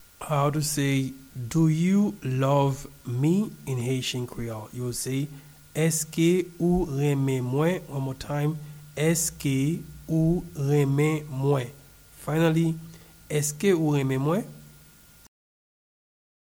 Pronunciation and Transcript:
Do-you-love-me-in-Haitian-Creole-Eske-ou-renmen-mwen-pronunciation.mp3